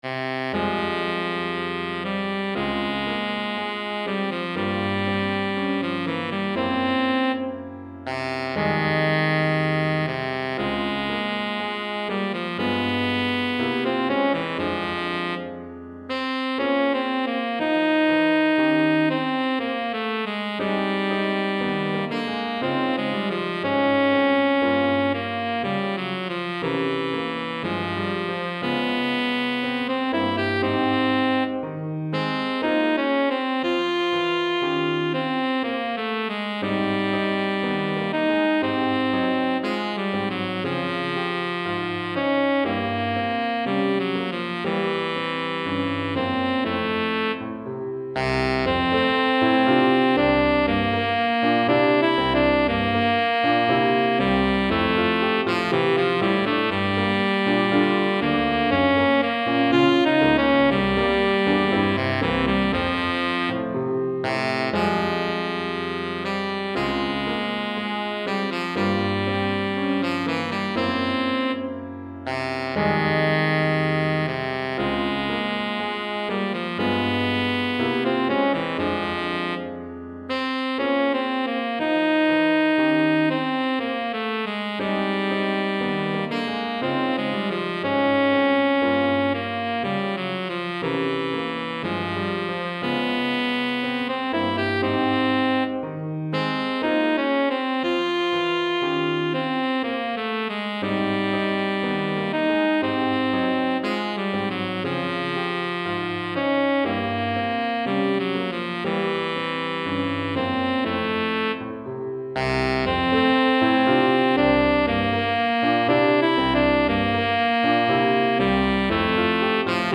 Saxophone Ténor et Piano